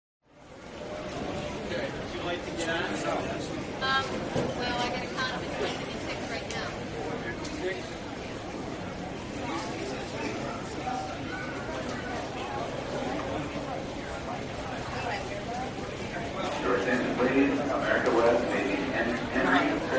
Airport Terminal
Airport Terminal is a free ambient sound effect available for download in MP3 format.
# airport # travel # crowd About this sound Airport Terminal is a free ambient sound effect available for download in MP3 format.
074_airport_terminal.mp3